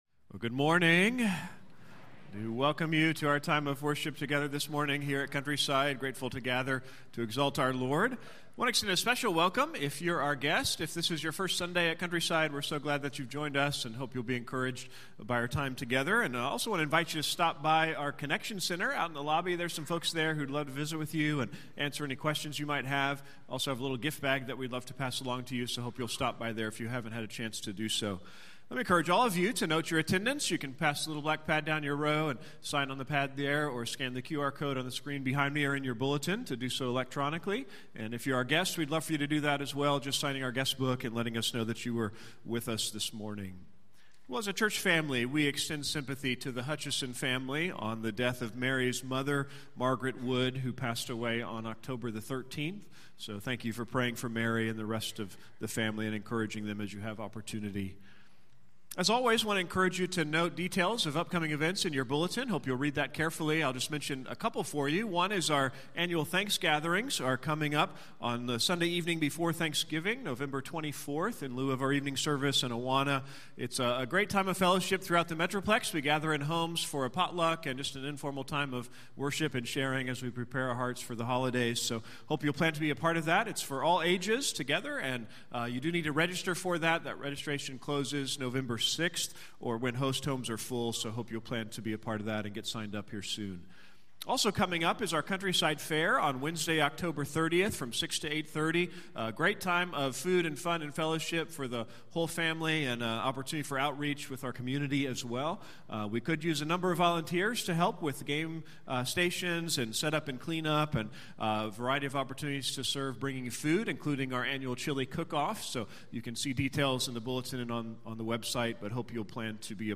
Morning Baptism & Communion Service